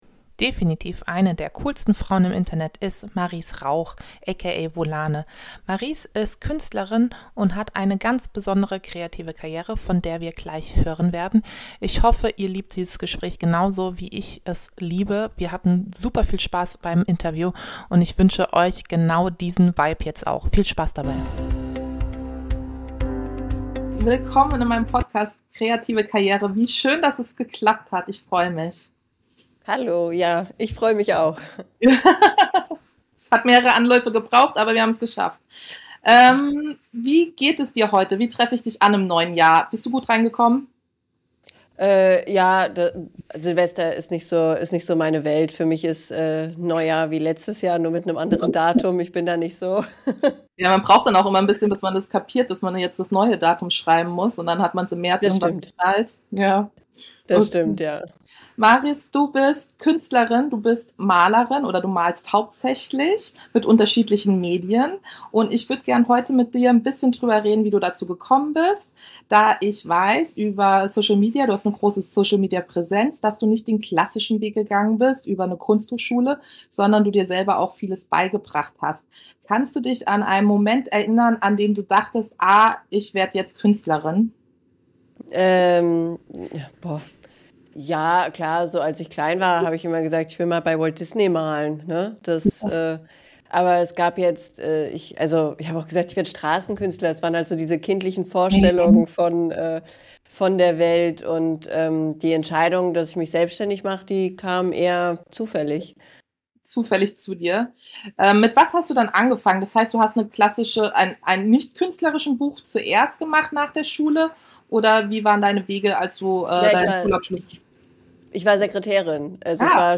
Diese Folge ist kein Hochglanz-Kunsttalk – sondern ein ehrliches Gespräch über Selbstvertrauen, Existenz und den Mut, seinen eigenen Weg zu wählen.